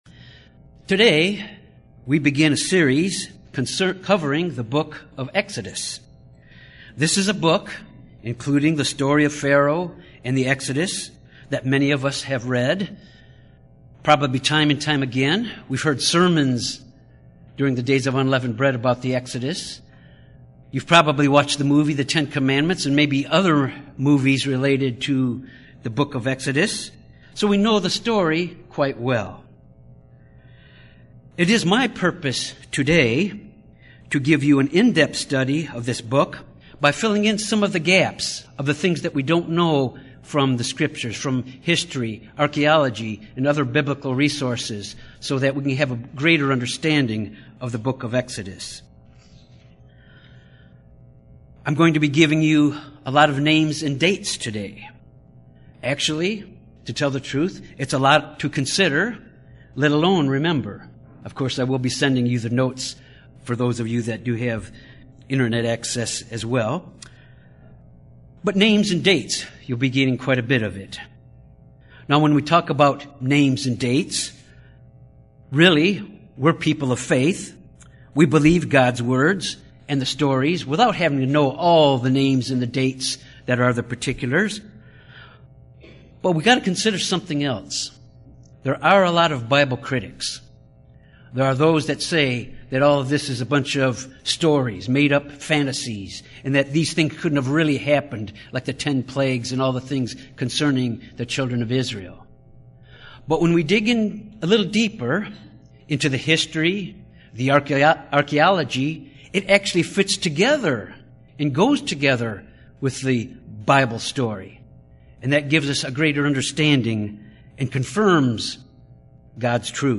This Bible Study provides background information on Egypt and the pharaohs as well as the background between the books of Genesis and Exodus.